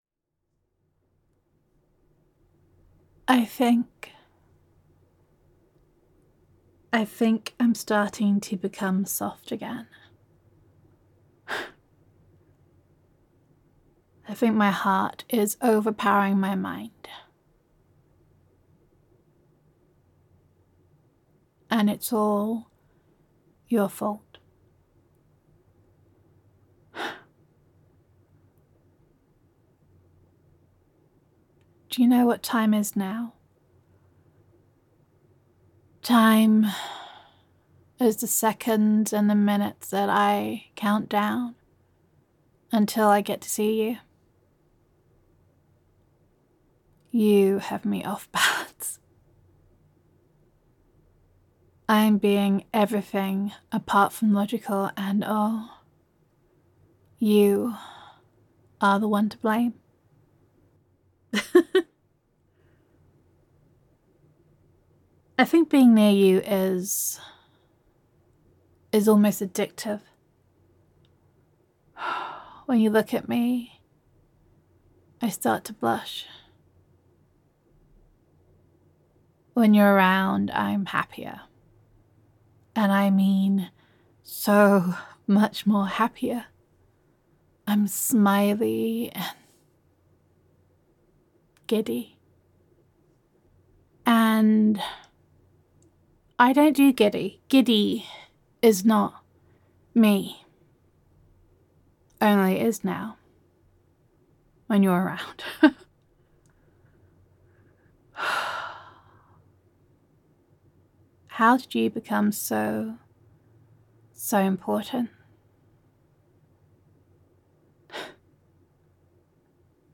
[F4A] Soft Again [Opening Up][Appreciation][Being Vulnerable][Falling for You][Confession][Feeling Is Dangerous][Girlfriend Roleplay][Gender Neutral][Your Girlfriend Is Falling for You]